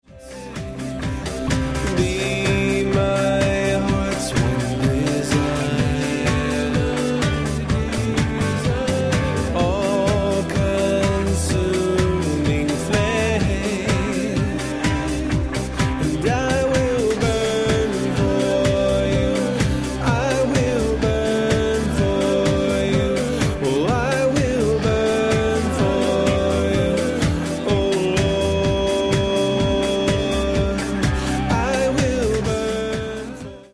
a broad spectrum of musical sounds